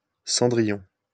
Cendrillon (French pronunciation: [sɑ̃dʁijɔ̃]